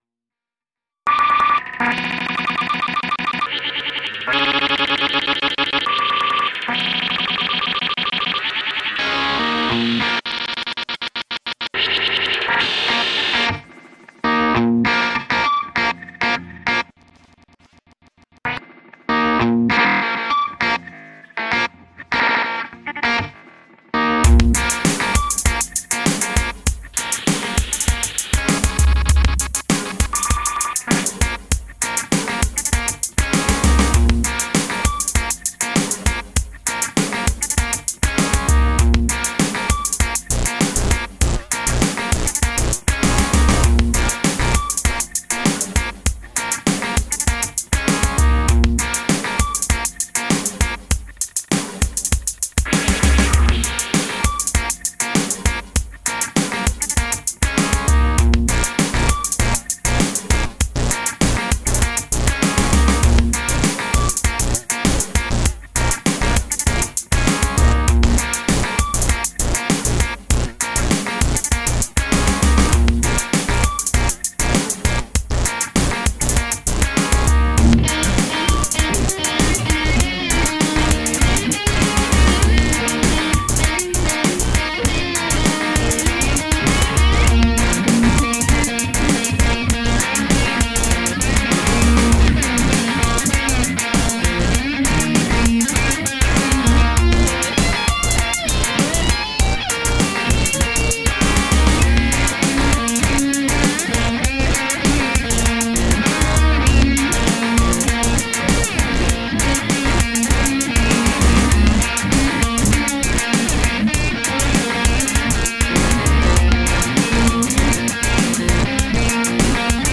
another slightly more LIVE version with free spirit slide voodoo blues guitar